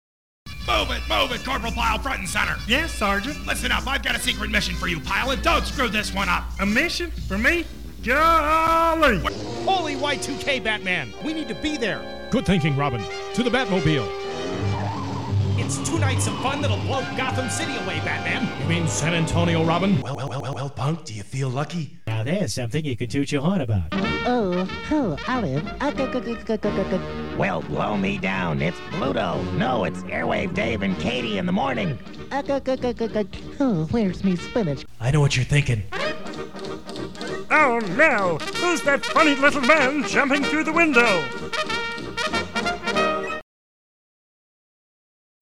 Voiceover Samples